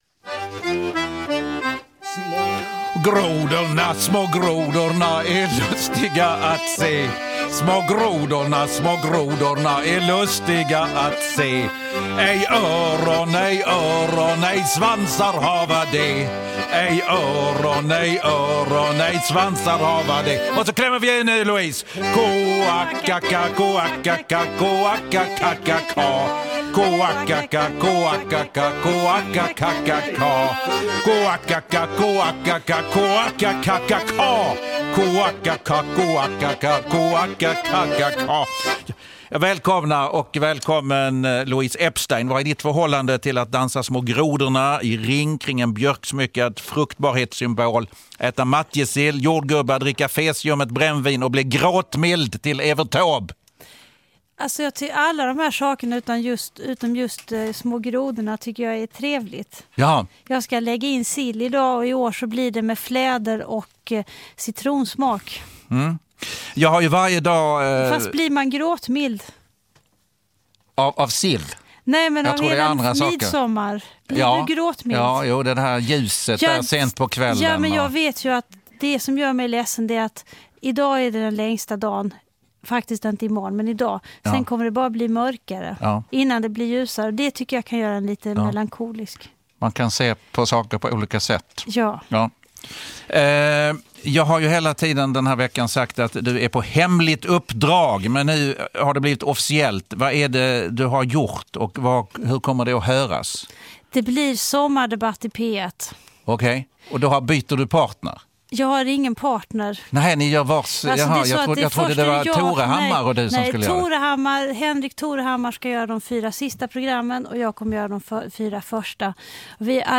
Dessutom en favorit i repris: dragspelaren Roland Cedermark!